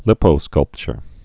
(lĭpō-skŭlpchər, līpō-)